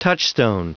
Prononciation du mot touchstone en anglais (fichier audio)
Prononciation du mot : touchstone